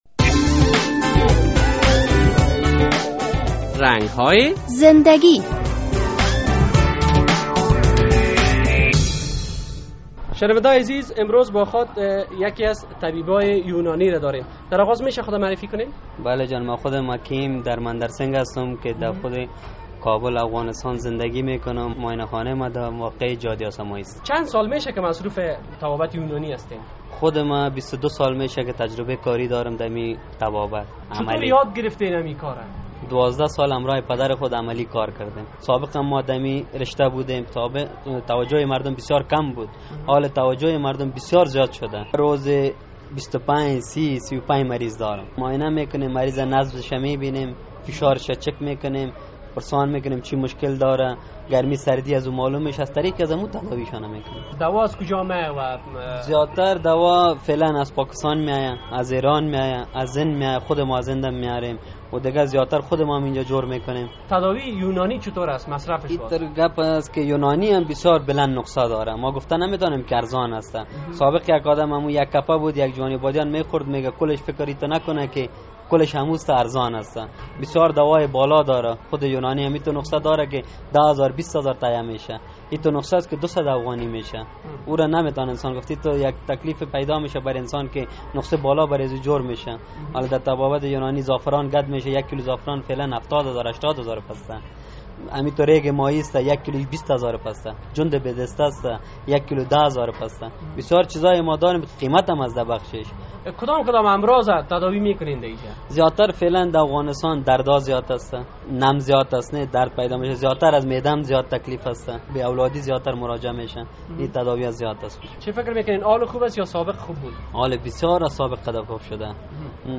مصاحبه کرده ایم